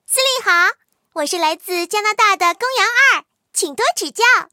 公羊2登场语音.OGG